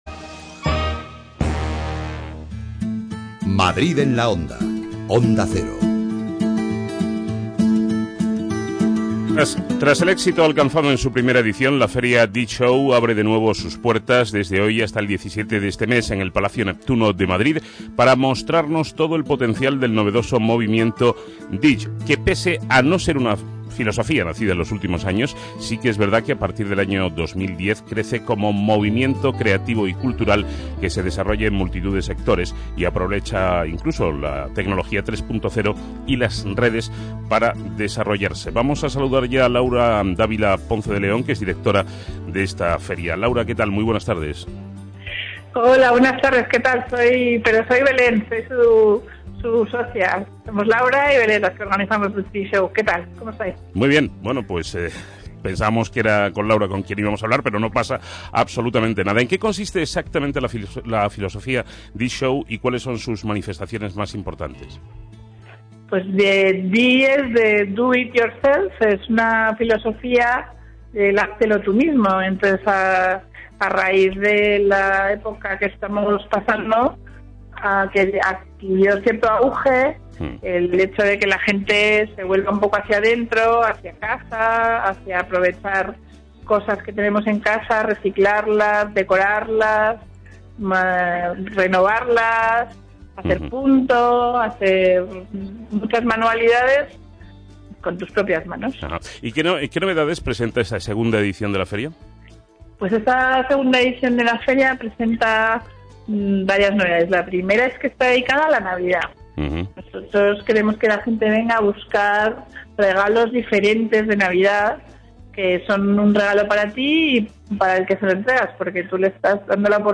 Entrevista a DIY SHOW en Madrid en la onda, Onda Cero (15-11-13)